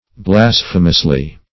Blasphemously \Blas"phe*mous*ly\, adv.